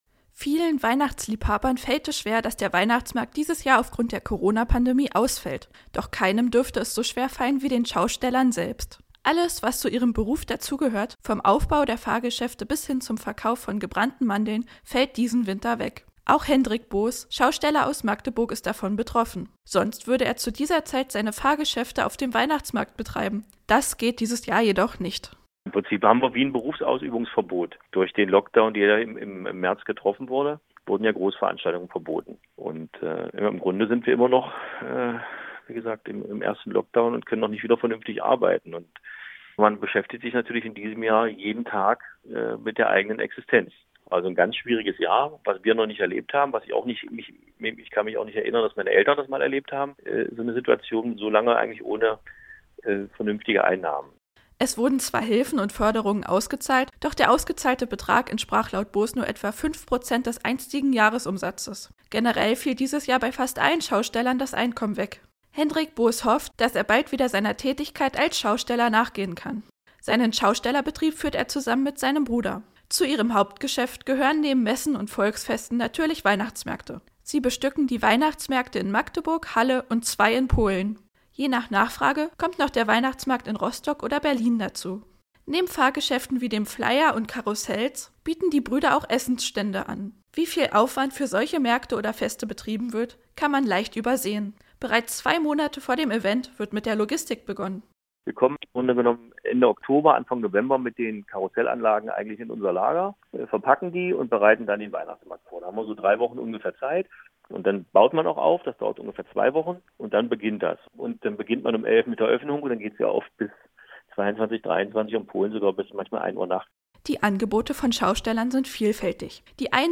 Wir haben uns mit einem Schausteller aus Magdeburg unterhalten und gefragt wie es in der Corona-Situation um die Schausteller steht.